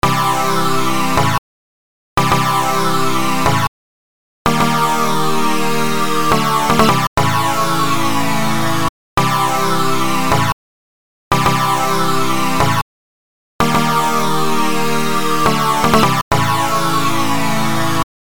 což je přesně tento C-E-G akord, pouze šoupaný nahoru a dolů